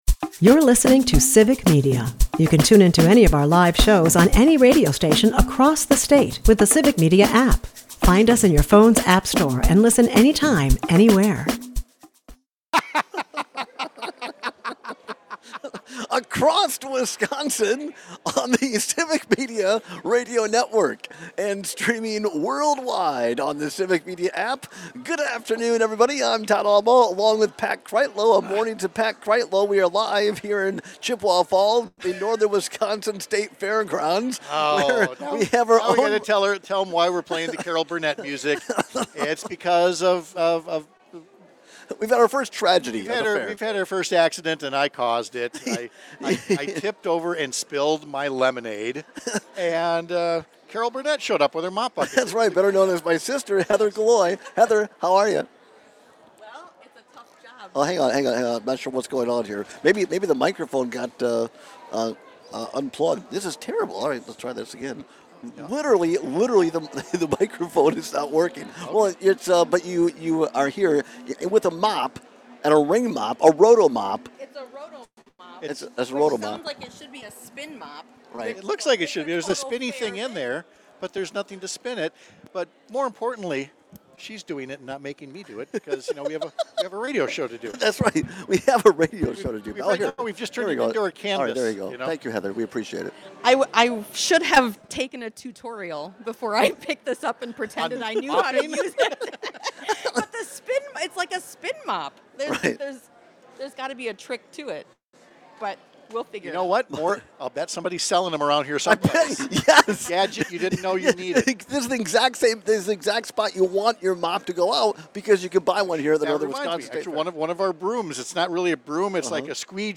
We take some calls and texts on which is more annoying.